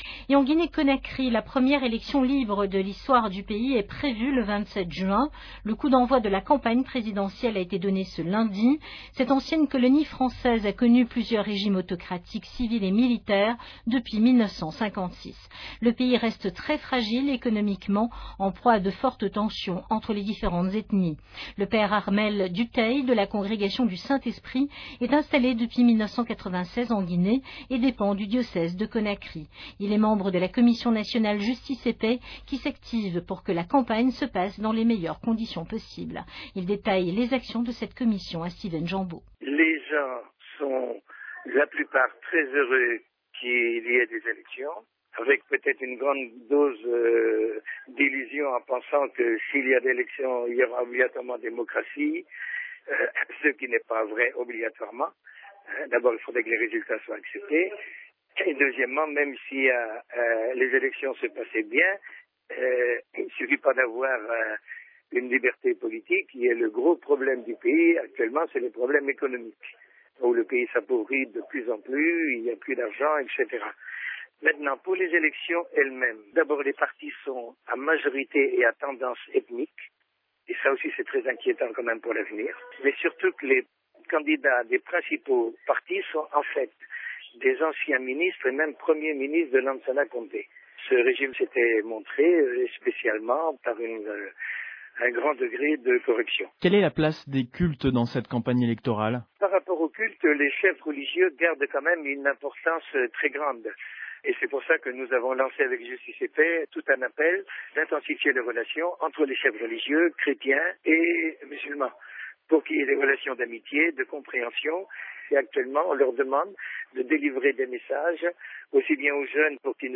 Une longue conversation téléphonique avec Radio Vatican